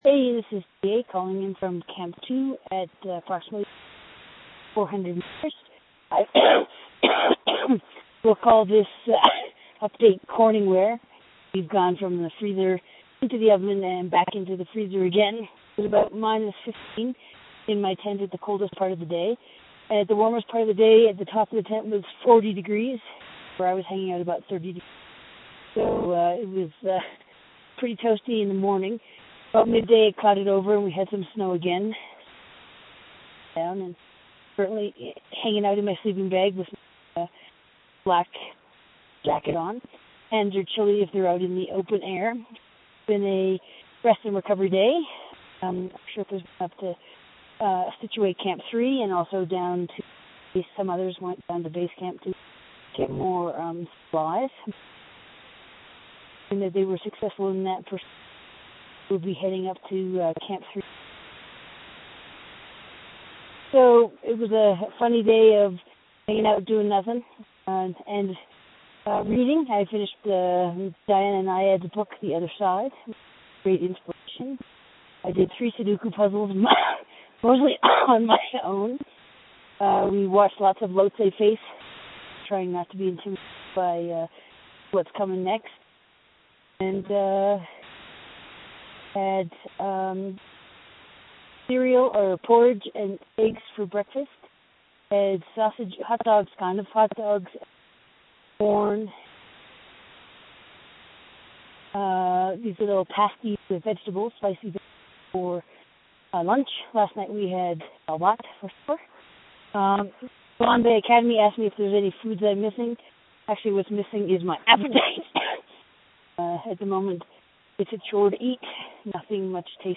Audio Post Camp 2 – Everest 3.0 Day 22